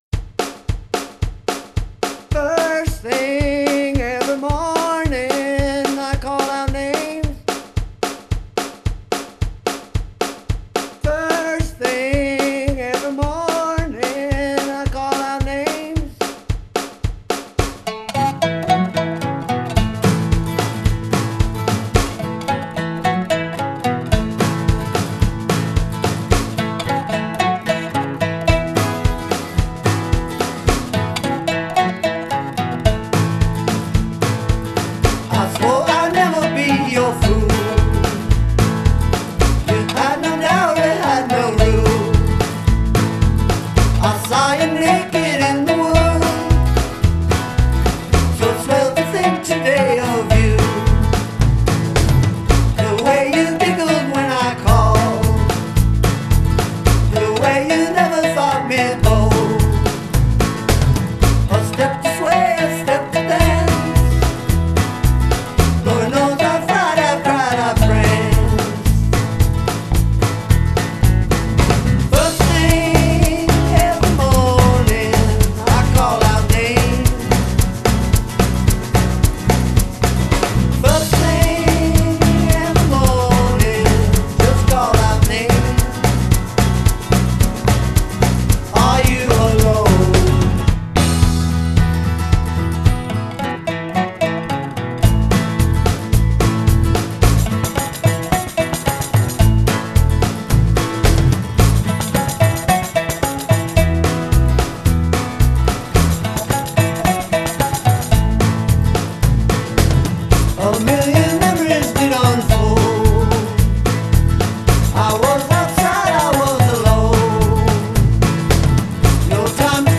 Guitar, Vocals, Drum Programming
Bass, Keyboards, Guitar, Drum Programming
Mandolin